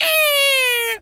pgs/Assets/Audio/Animal_Impersonations/bird_large_squawk_05.wav at master
bird_large_squawk_05.wav